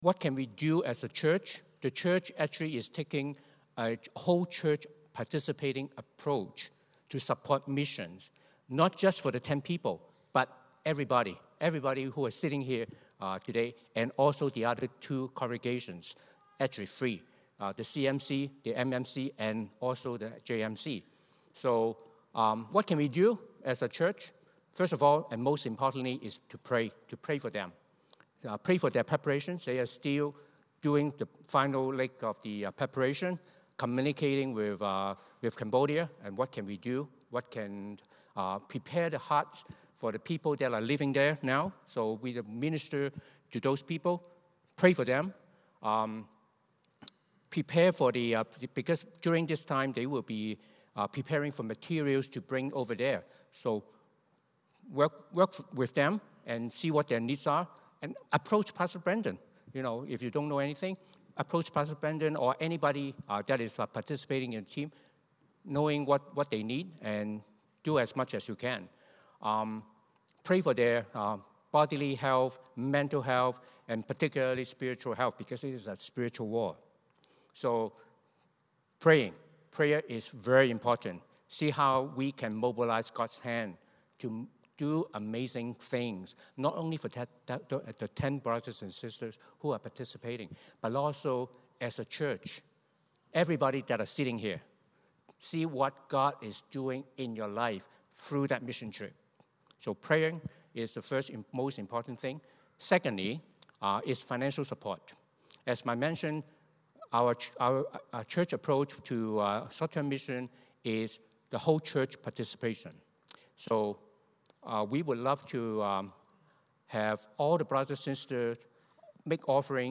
Romans 8:1-17 Service Type: Sunday Morning Service Questions